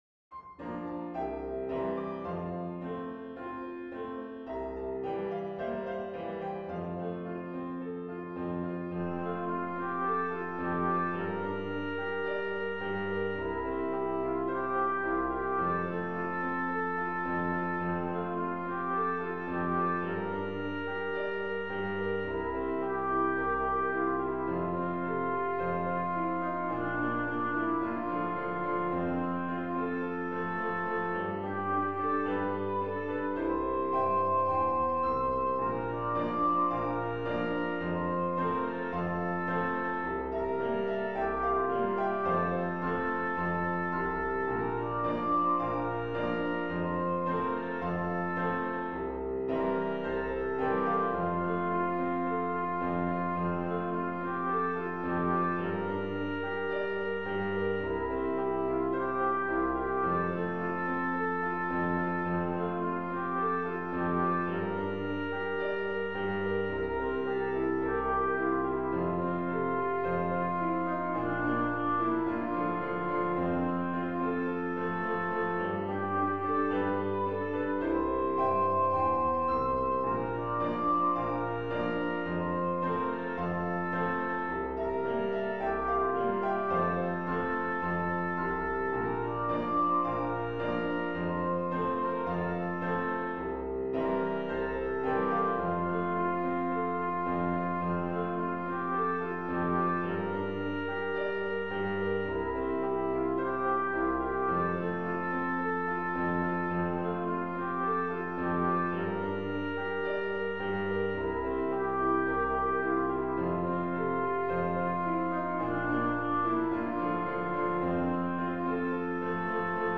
校歌 歌詞 校歌 歌詞 (PDFファイル: 955.7KB) 校歌メロディ 王寺北義務教育学校 校歌メロディ (音声ファイル: 3.6MB) 王寺南義務教育学校 校歌メロディ (音声ファイル: 4.4MB)